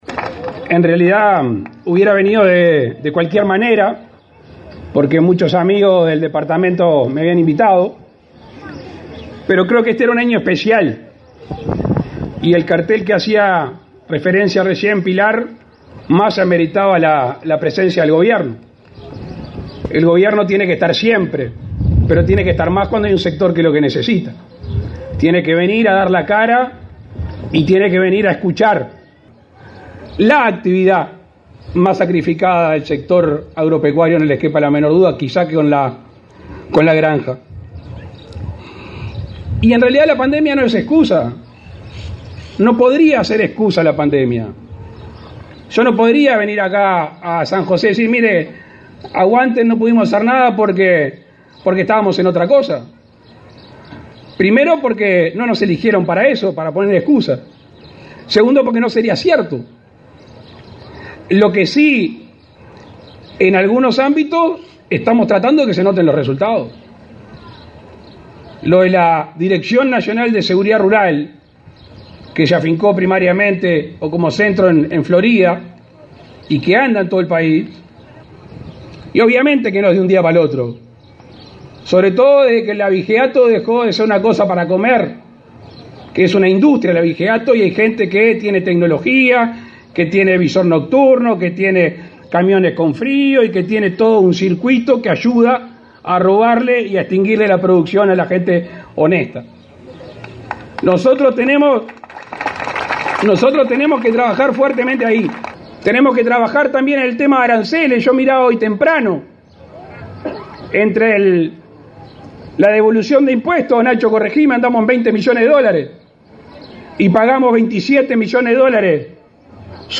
El presidente de la República, Luis Lacalle Pou, participó este domingo 11 de  la ceremonia de clausura de la 76.ª edición de la Exposición Internacional de Ganado Lechero, en San José. En su discurso Lacalle Pou, valoró las demandas del sector y aseguró que la pandemia no es excusa para resolver los problemas.